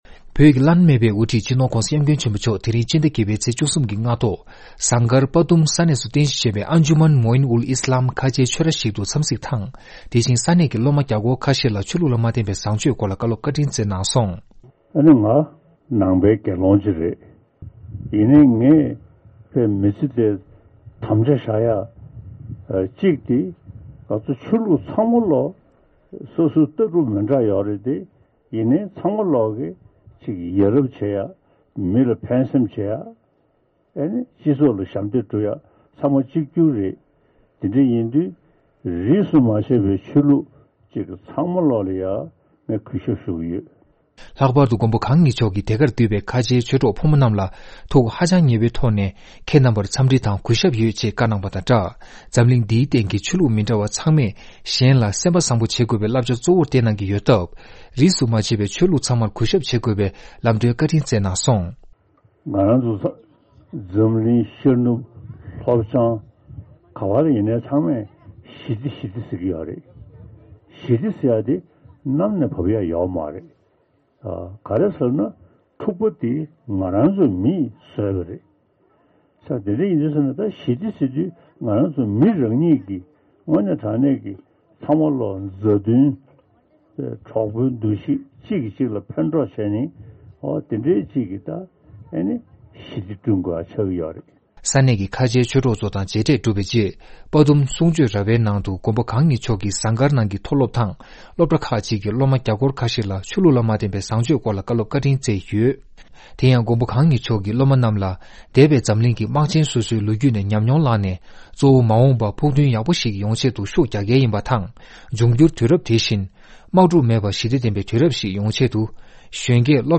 ས་གནས་ནས་སྙན་སྒྲོན་ཞུས་ཡོད།